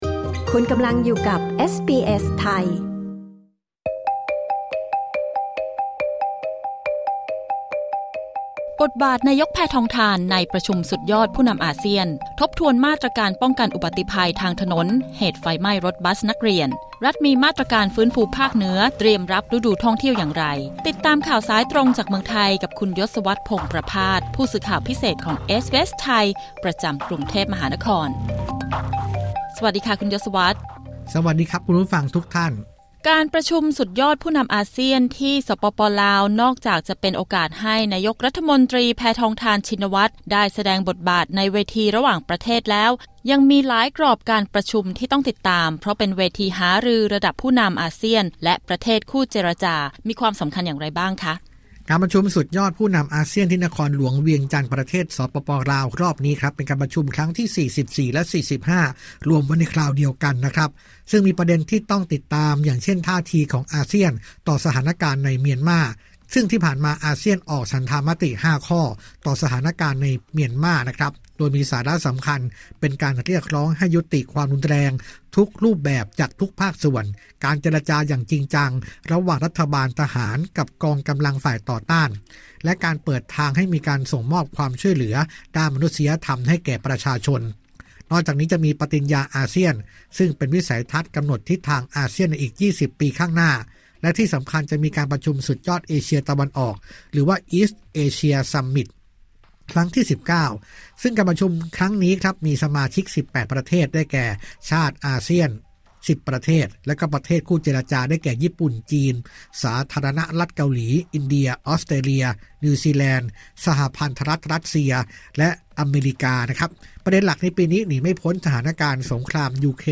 กด ▶ ฟังรายงานข่าวด้านบน